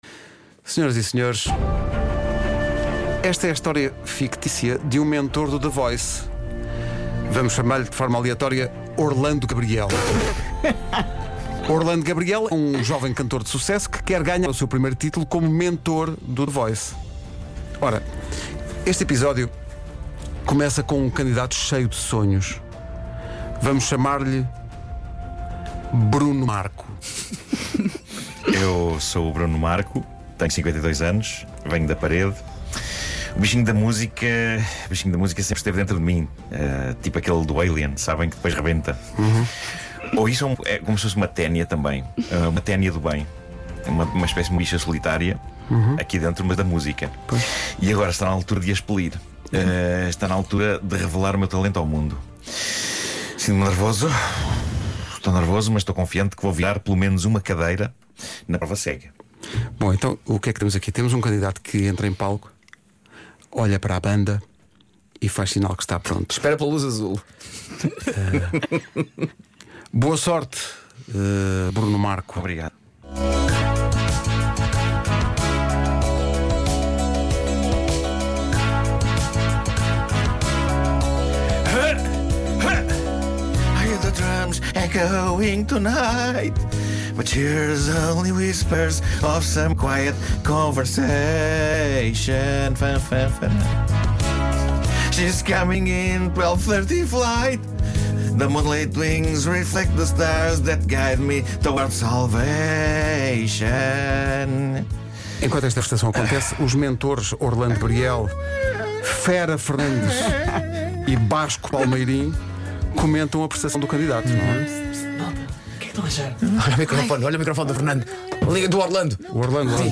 Radionovela das Manhãs da Comercial